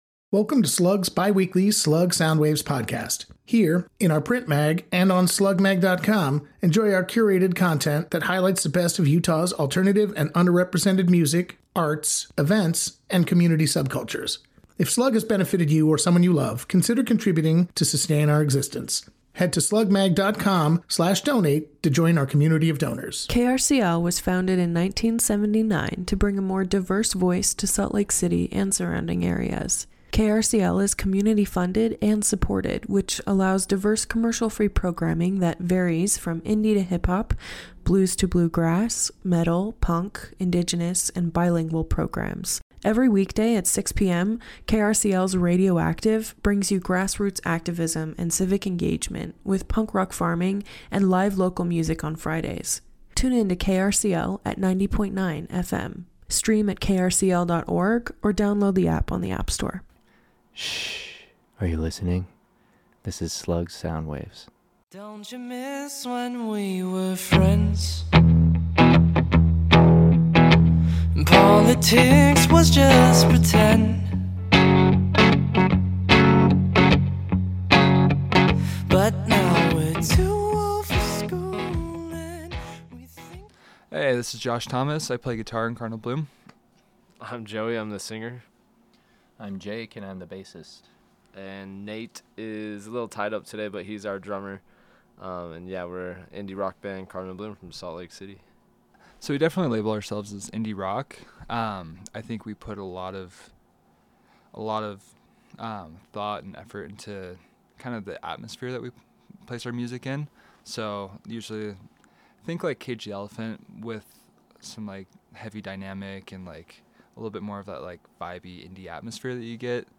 On this episode of SLUG Soundwaves, the group chats about the bands that got them into playing music, the origin of the group itself and their songwriting process.